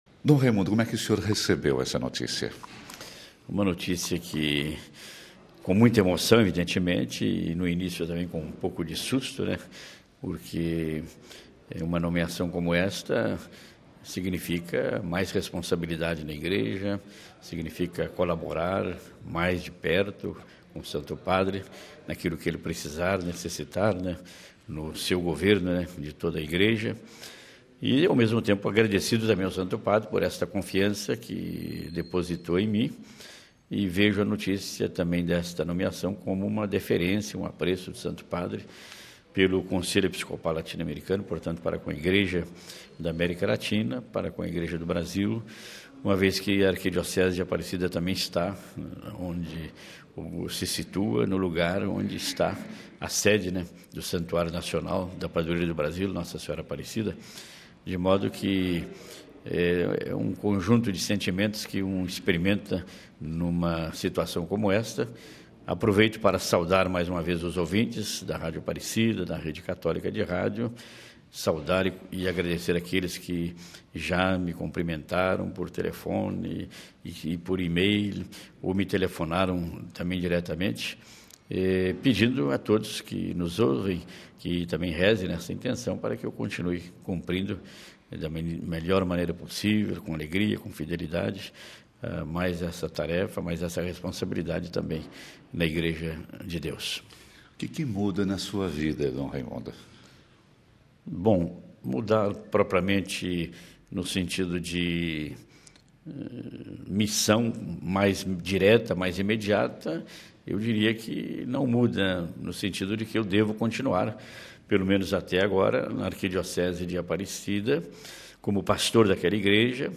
DOM DAMASCENO FALA SOBRE O SEU FUTURO CARDINALATO